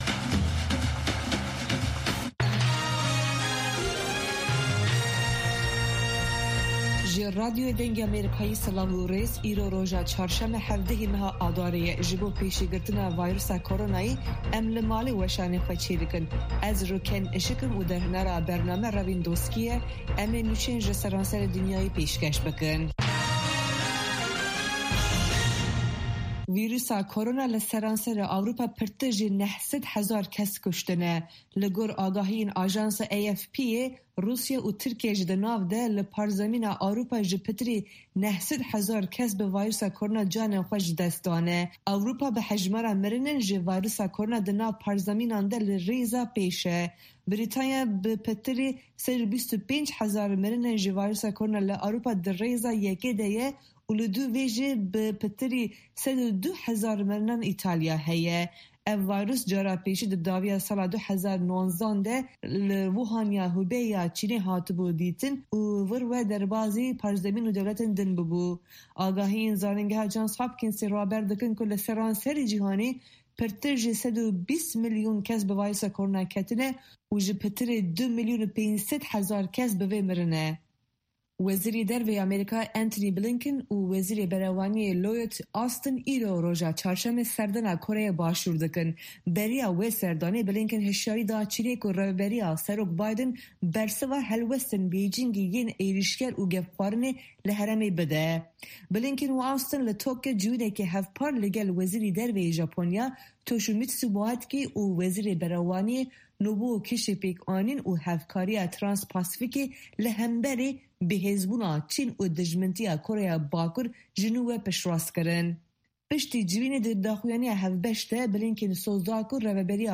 هه‌واڵه‌کان، ڕاپـۆرت، وتووێژ،